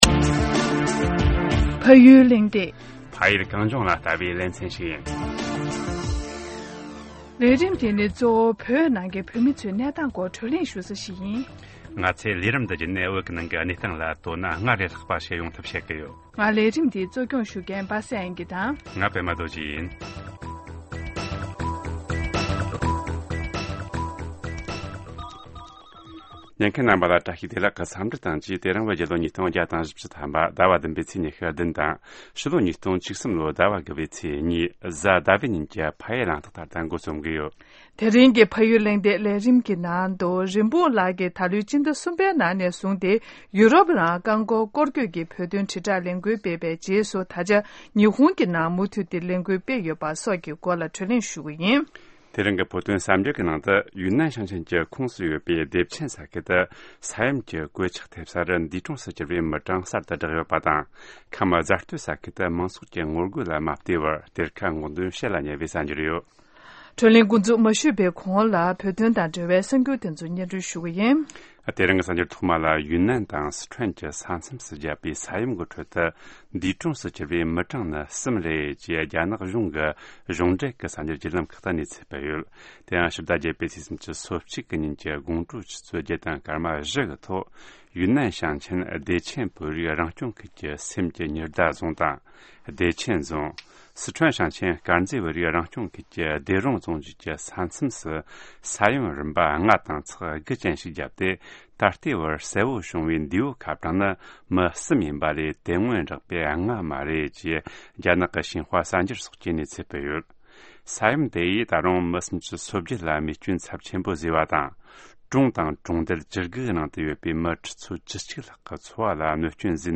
ཞལ་པར་ཐོག